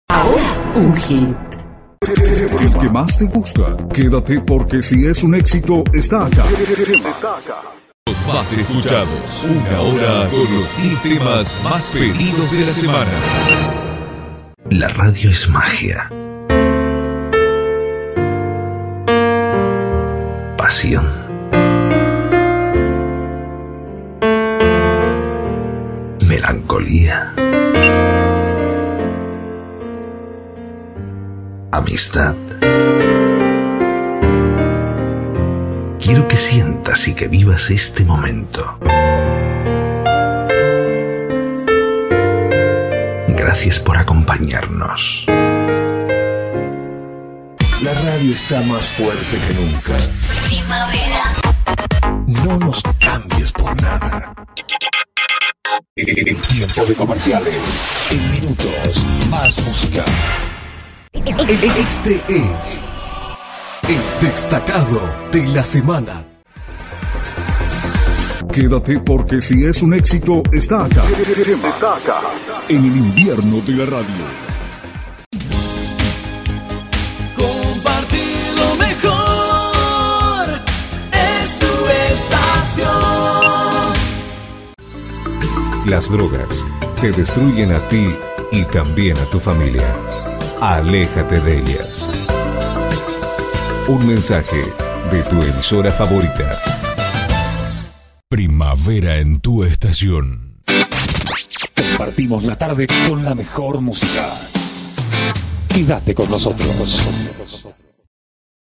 ARTISTICA RADIO GENERICA SPOTS - FMdepot-
ARTE RADIAL - VESTIMENTA PARA EMISORAS - LOCUCIONES GENERICAS LOCUCIONES PERSONALIIZADAS - SPOTS PUBLICITARIOS - SEPARADORES - JINGLES - CUÑAS -